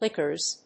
/ˈlɪkɝz(米国英語), ˈlɪkɜ:z(英国英語)/